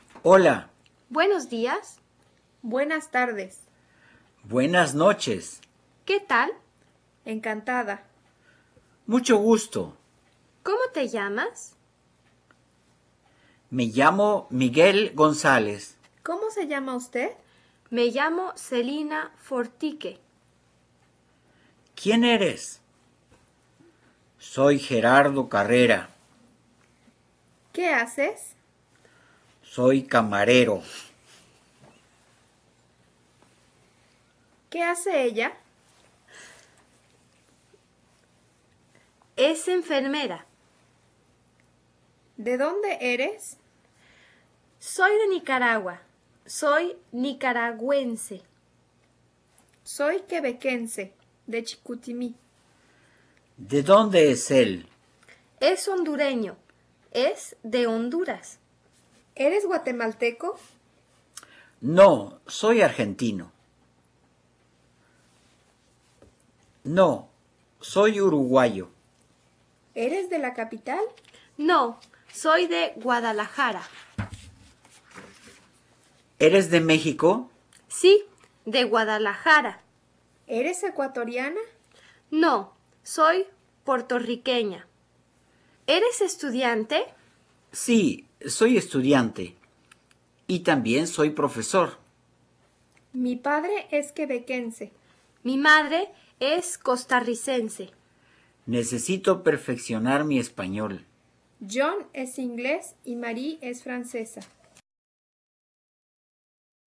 Escucha y repite
Escuchen las frases del ejercicio. Repitan las frases poniendo atención en la pronunciación.